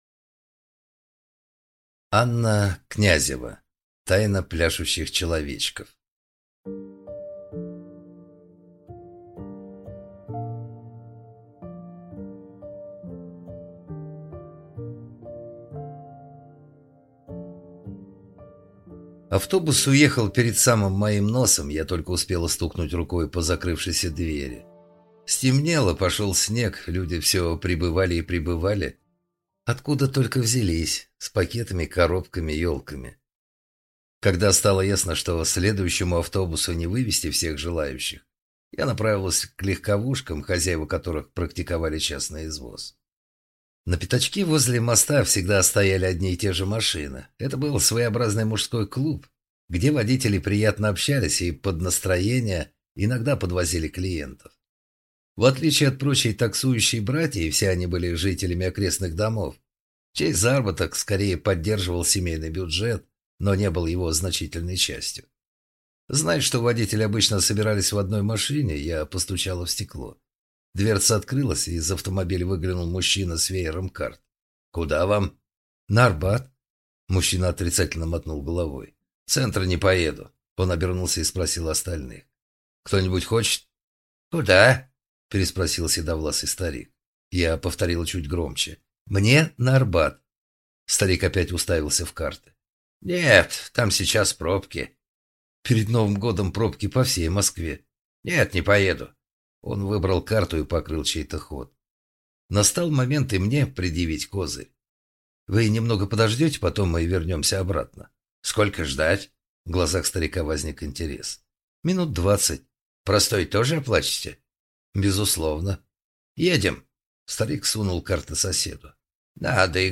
Аудиокнига Тайна пляшущих человечков | Библиотека аудиокниг